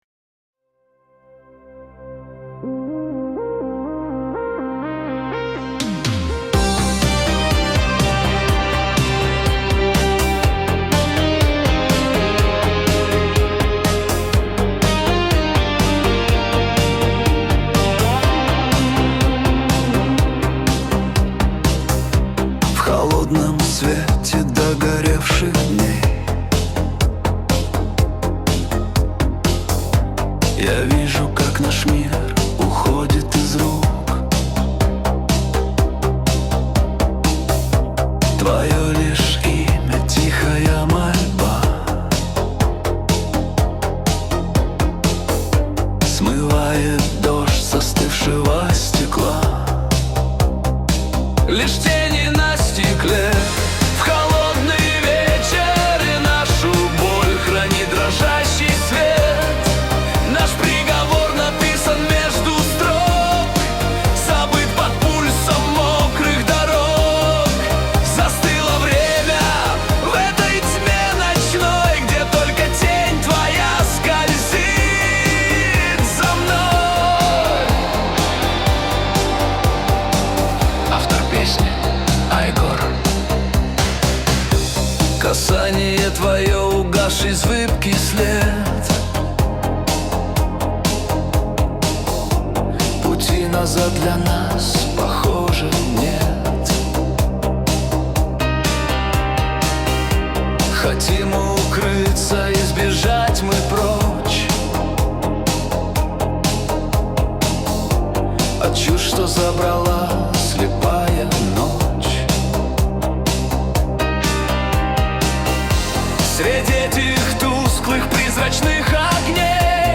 Нейросеть Песни 2025, Стихи